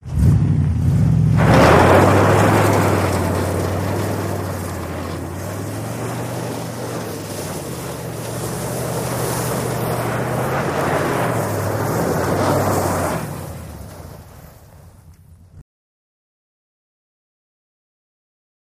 Sports Car Spin Out In Gravel